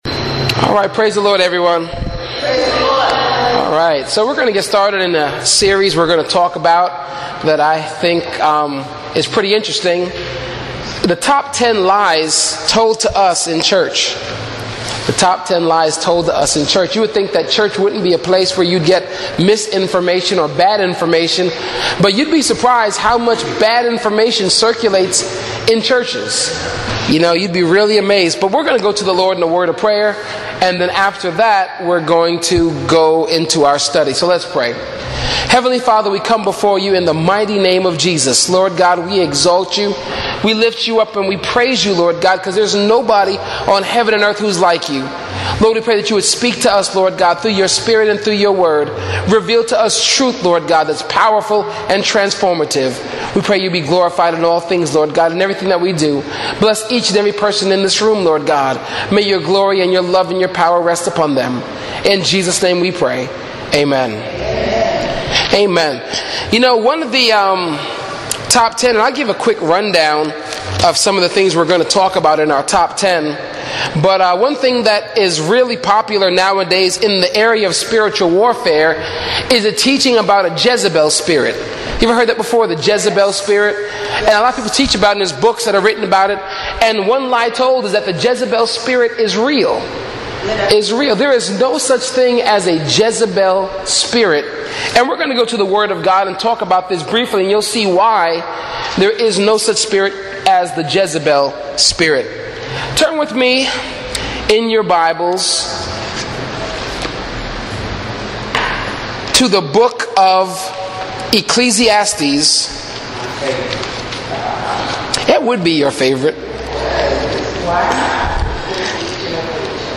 AUDIO BIBLE CLASSES